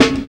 69 SNARE 3.wav